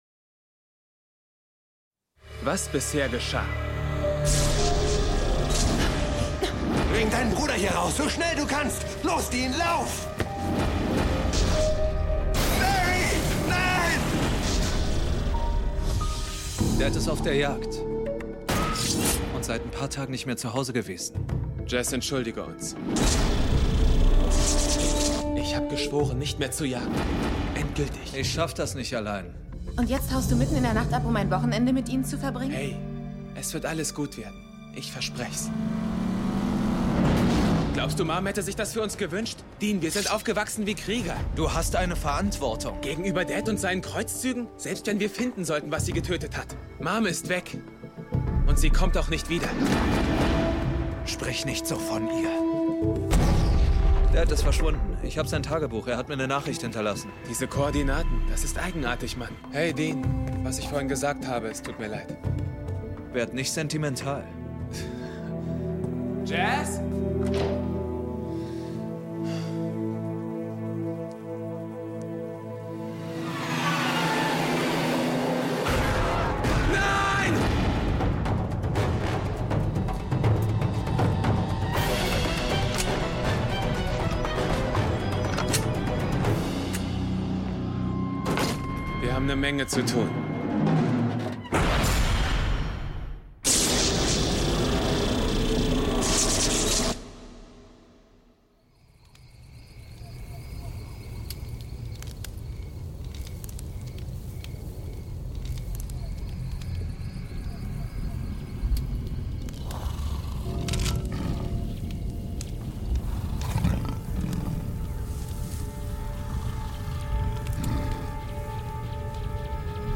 S01E02: Wendigo - Supernatural Hörspiel Podcast